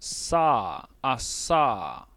26 s consonant fricative alveolar unvoiced [
voiceless_alveolar_fricative.wav